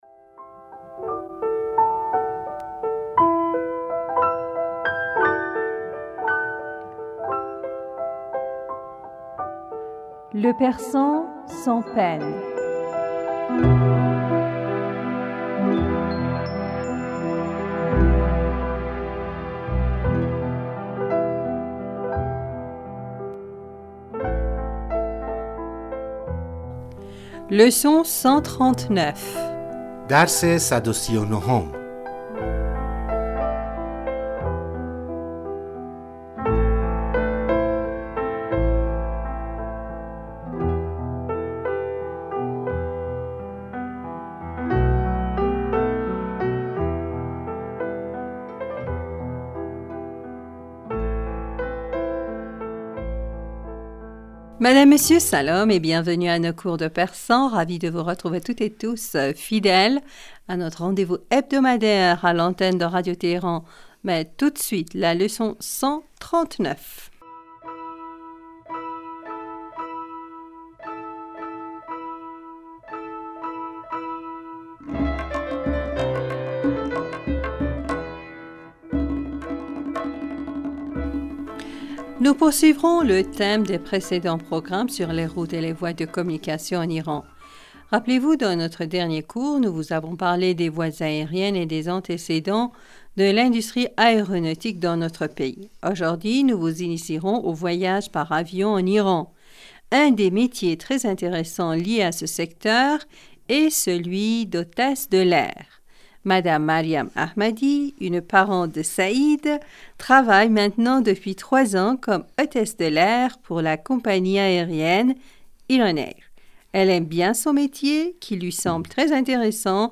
Madame, Monsieur, Salam et bienvenus à nos cours de persan.